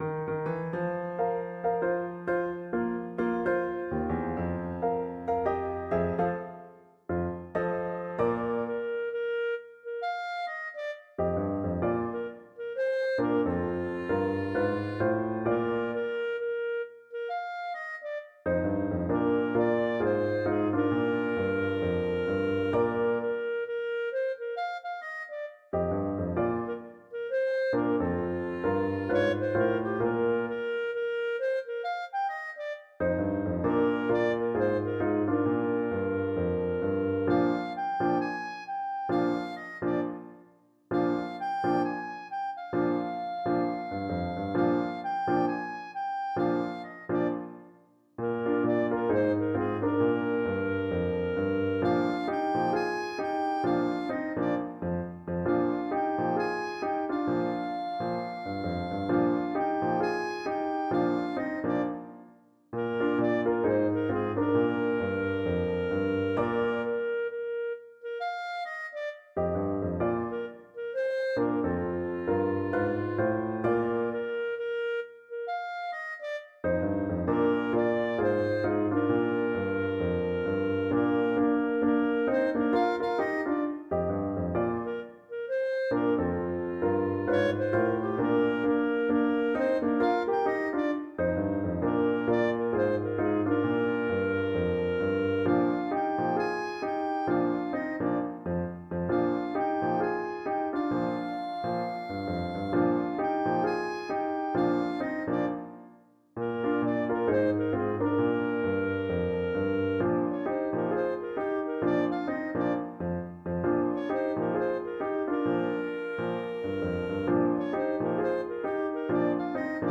A jazzy, swing edition of traditional American Folk tune
Jazz and Blues